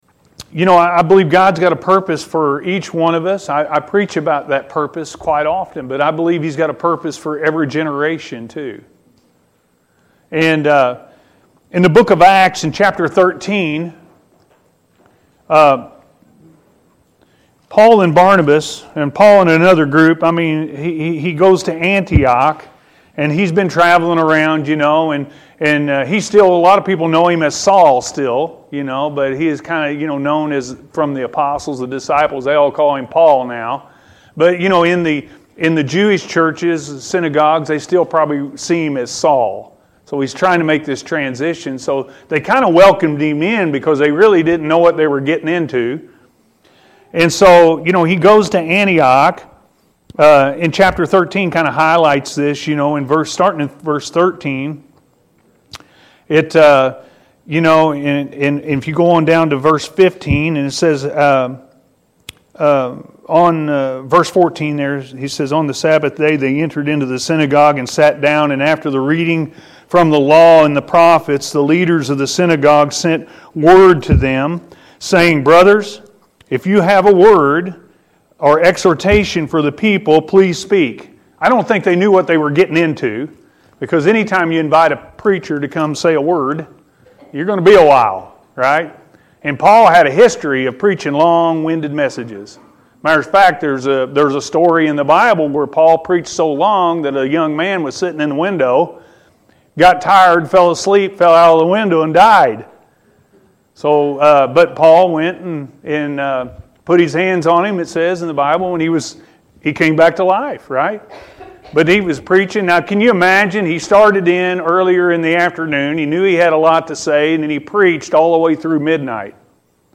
God Has A Purpose For Everyone-A.M. Service